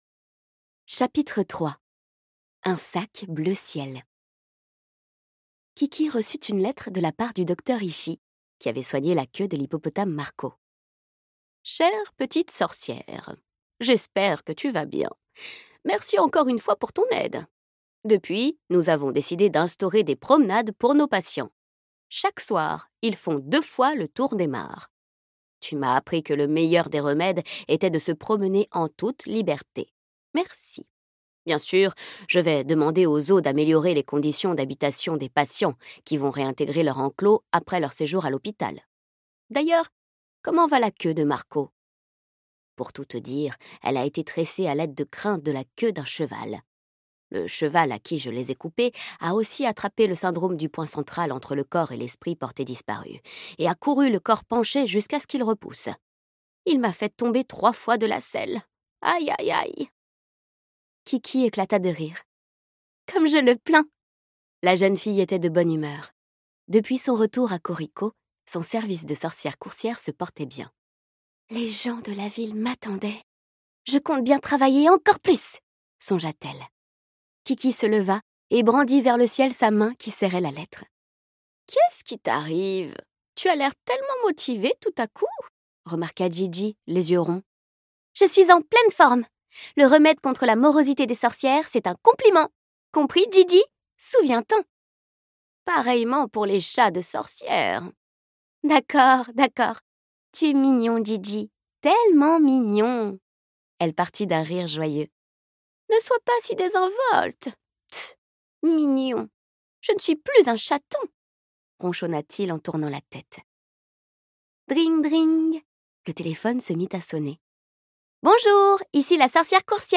Alors que le doute germe dans son esprit, la jeune sorcière en vient même à remettre en question son service de livraison…Ce livre audio est interprété par une voix humaine, dans le respect des engagements d'Hardigan.